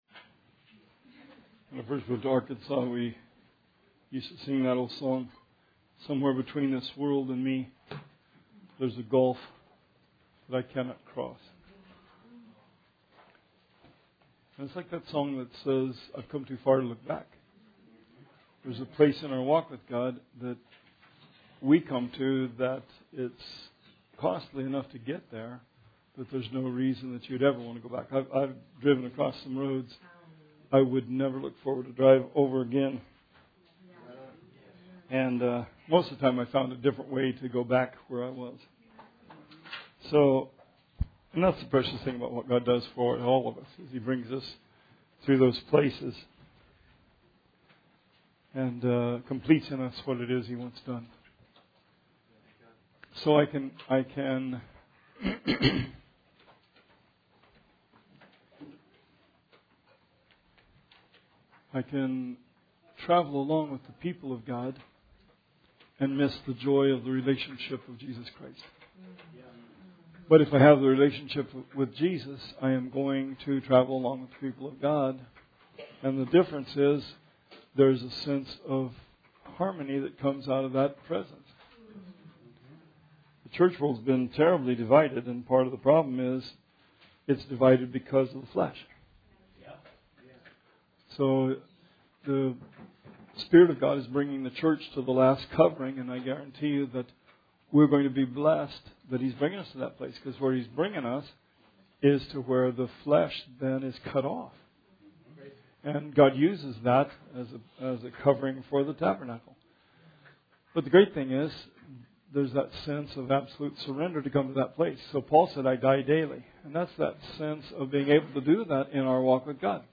Bible Study 11/29/17